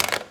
Colgar el teléfono